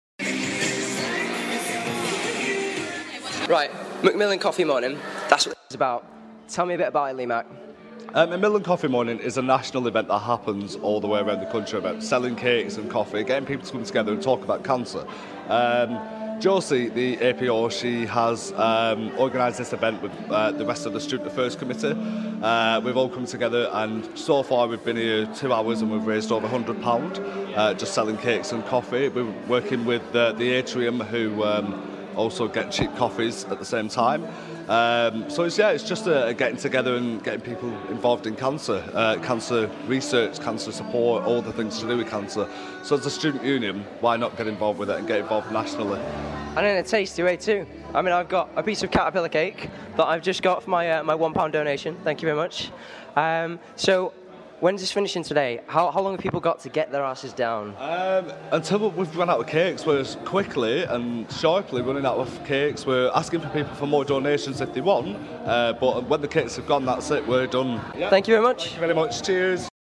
The event is in full swing, and everyone's jostling for cake...